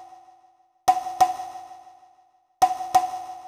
PITCH PERC-L.wav